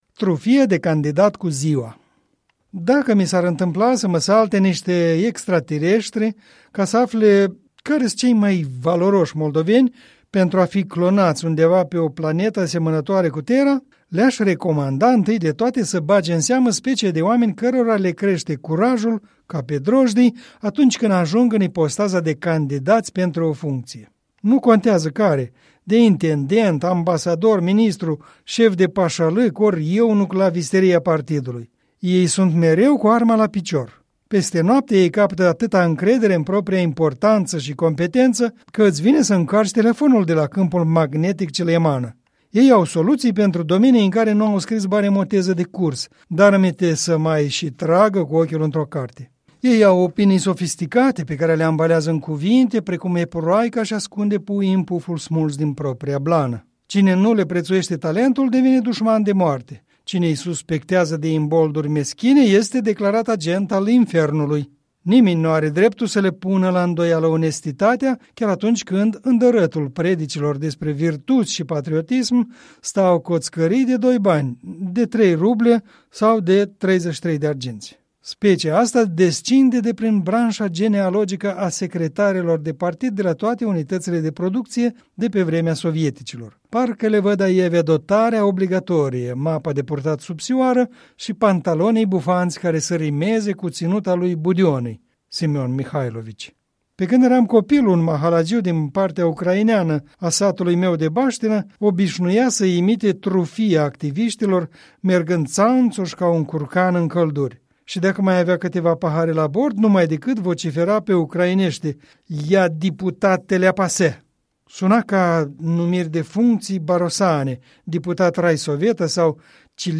Un minut de comentarii electorale...